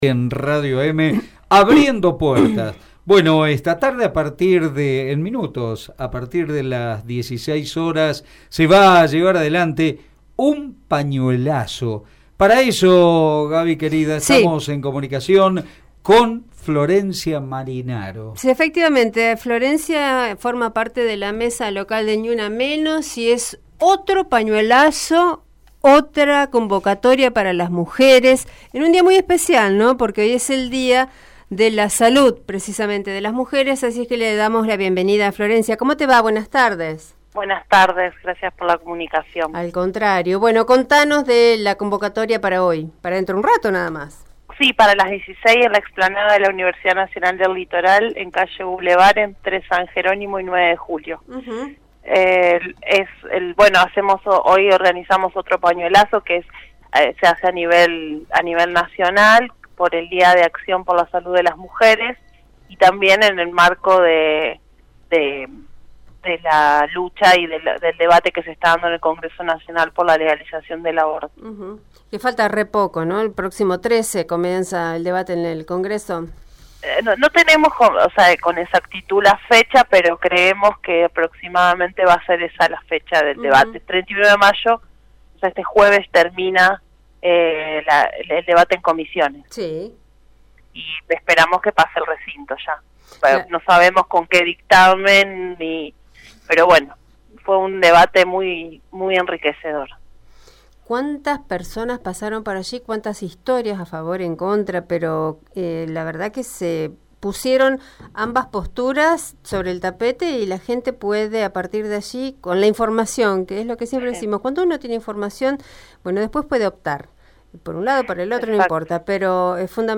La entrevistada habló sobre la manifestación de está tarde y sobre el debate por la despenalización del aborto.